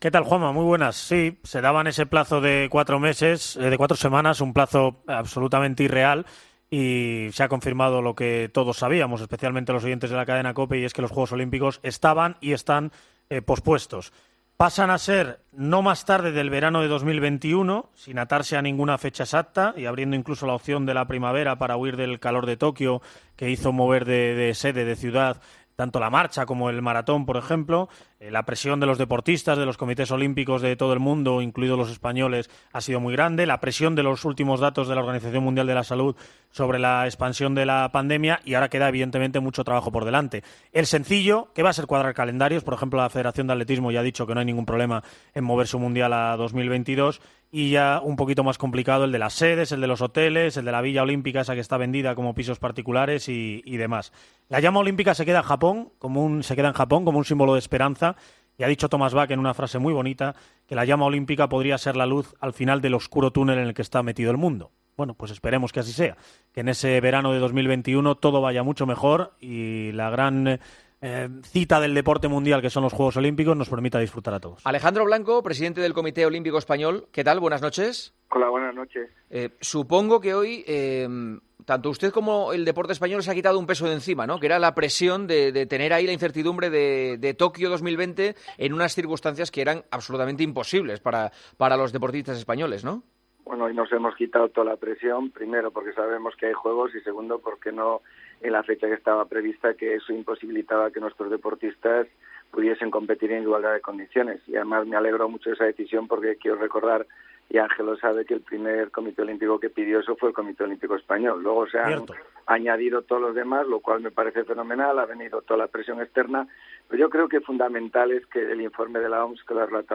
AUDIO - ENTREVISTA A ALEJANDRO BLANCO, EN EL PARTIDAZO DE COPE